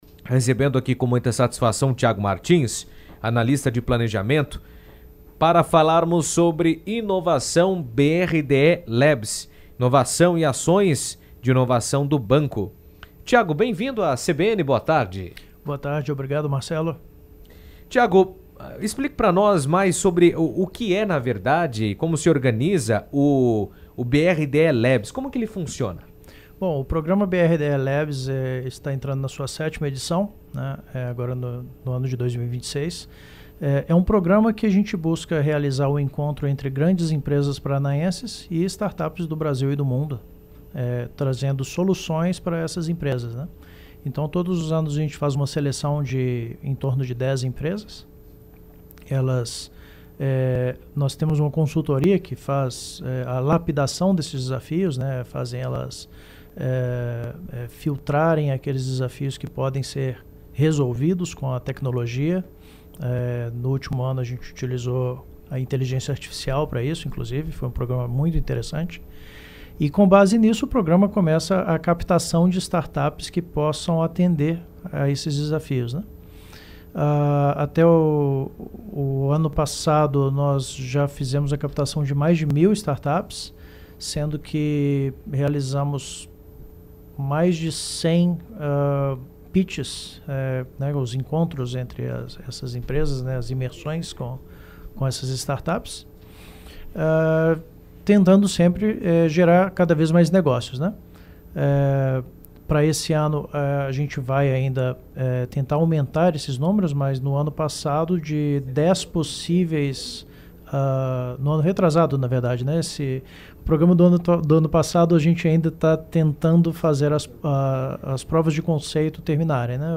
esteve no estúdio da CBN durante a 38ª edição do Show Rural Coopavel e falou sobre o BRDE Labs